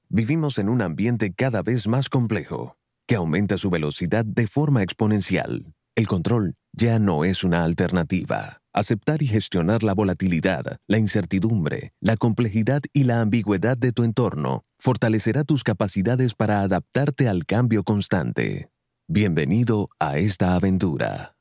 Male
From commercials to narration, audiobooks, podcasts, and radio/TV voiceovers, I offer a neutral Spanish accent that fits a wide range of styles. My voice is smooth, professional, and easy to connect with.
E-Learning